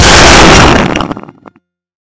Small Concert Hall Acoustics
small_concert_hall_impulse_response.wav